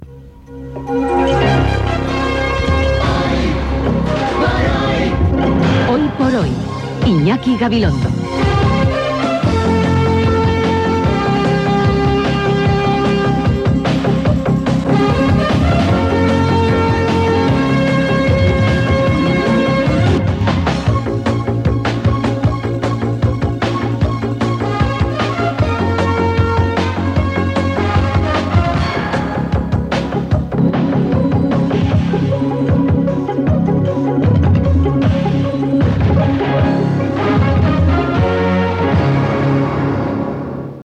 Indicatiu del programa i capçalera musical